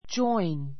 join 小 A1 dʒɔ́in ヂョ イン 動詞 ❶ 参加する , （仲間に） 入る, ～といっしょになる join a party join a party パーティーに参加する We are going on a picnic tomorrow.